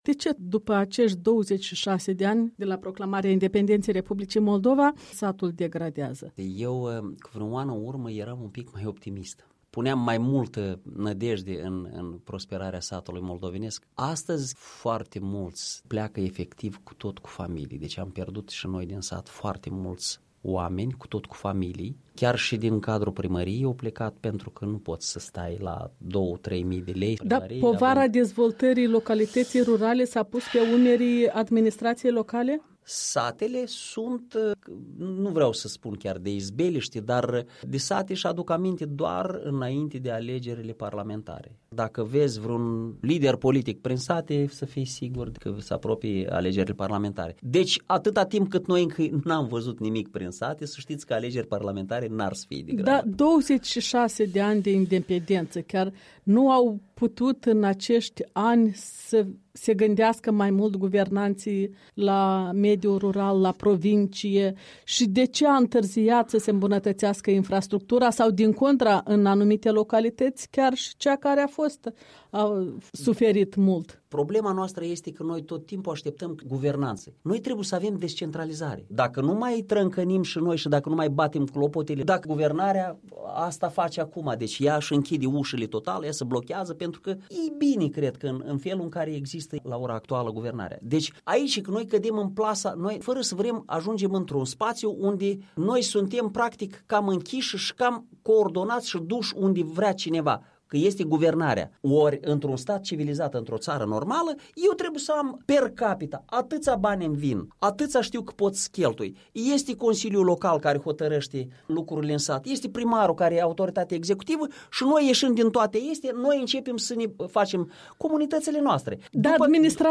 O discuție cu primarul comunei Capaclia din raionul Cantemir despre soarta satului moldovean în anii scurși de la independență.